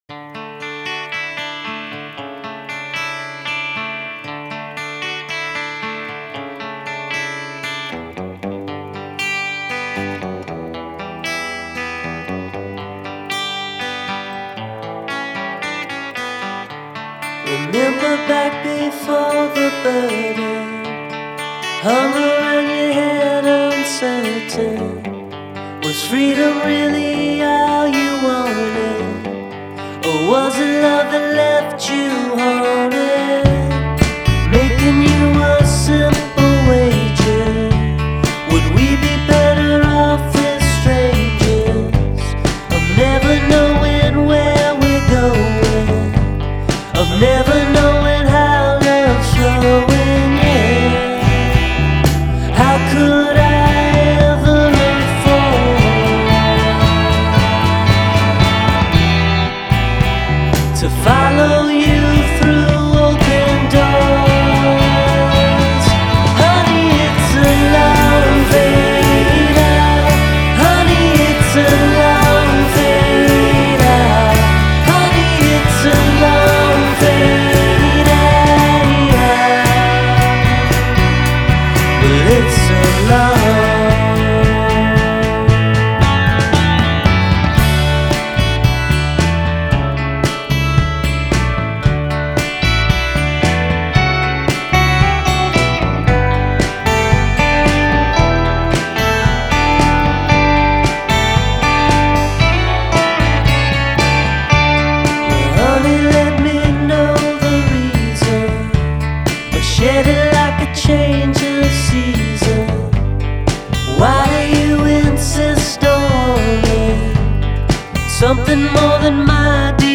c’est avant tout la douceur des mélodies